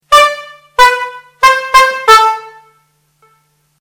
Klaxons à poire, jeu de 4 notes accordées